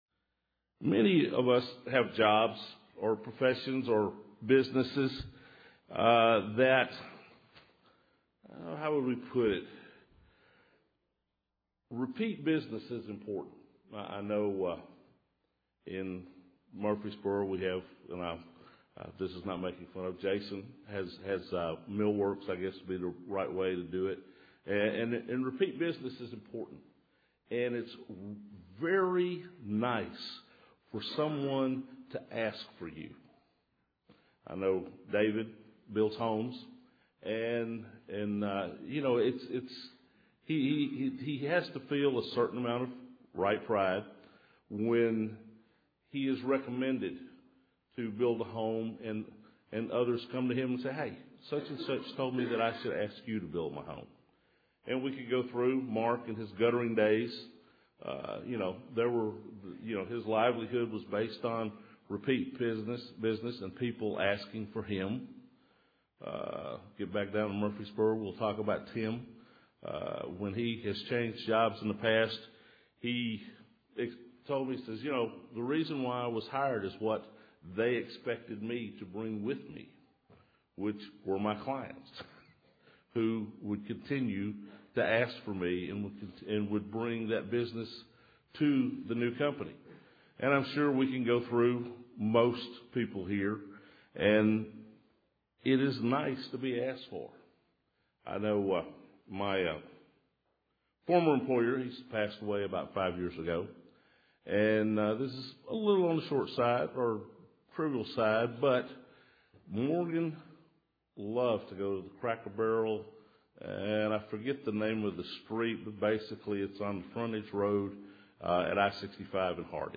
Their experience shows us the way to spiritual growth and maturity. This sermon was given on the Last Day of Unleavened Bread.